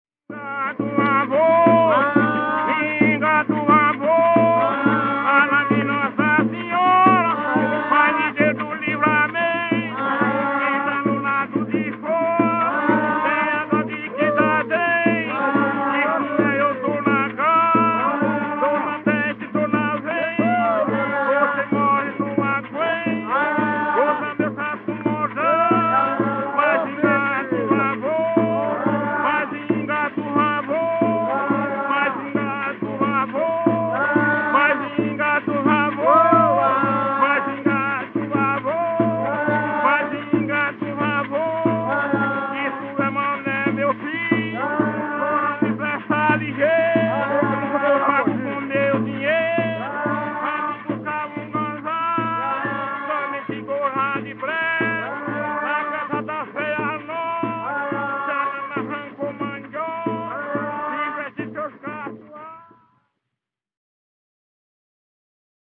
Coco embolada